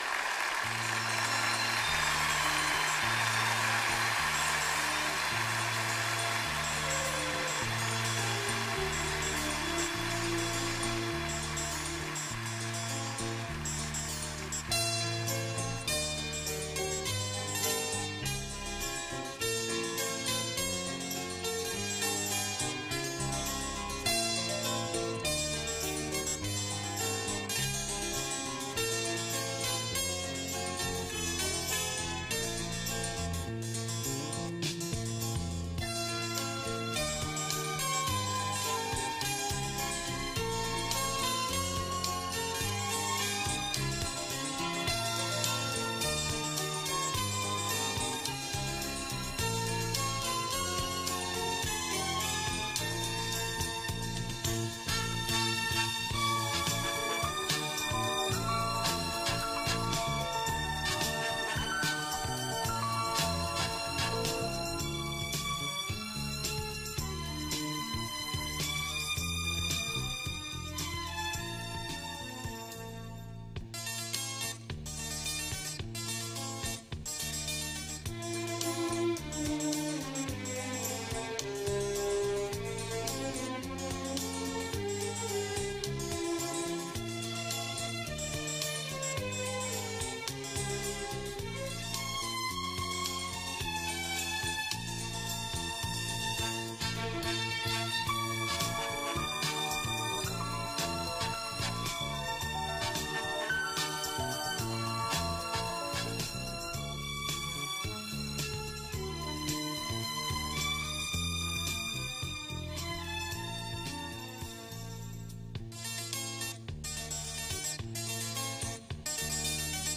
С концерта: